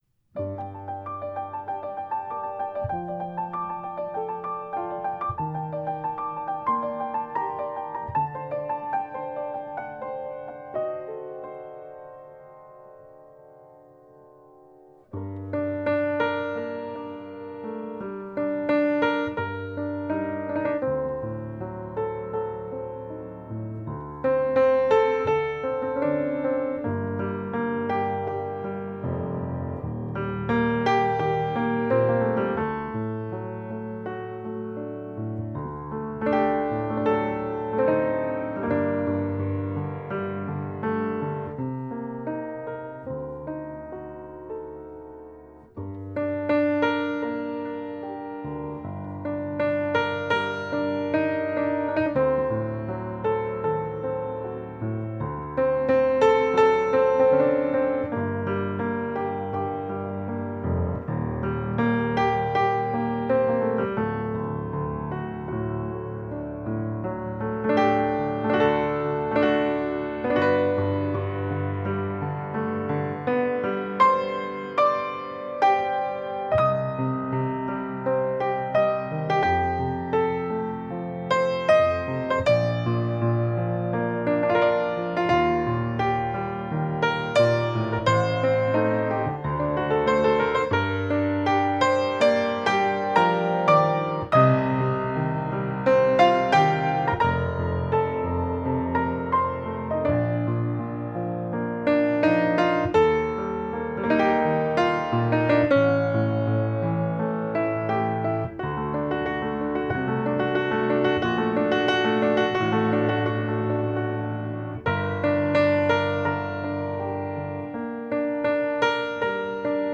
Классика